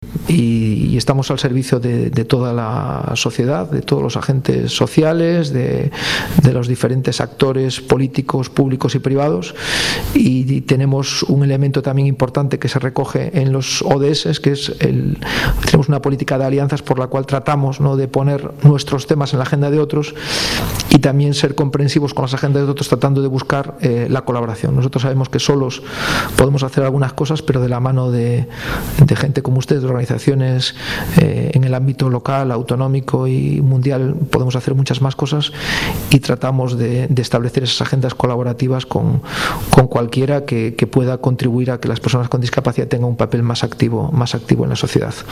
Ante una audiencia que llenó a rebosar el Salón Sardinero del Hotel Real de Santander